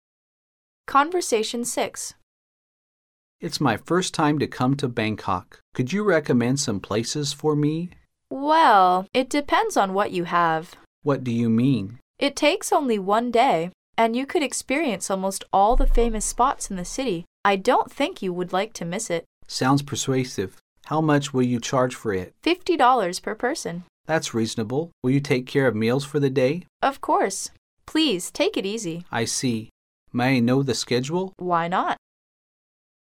Conversation 6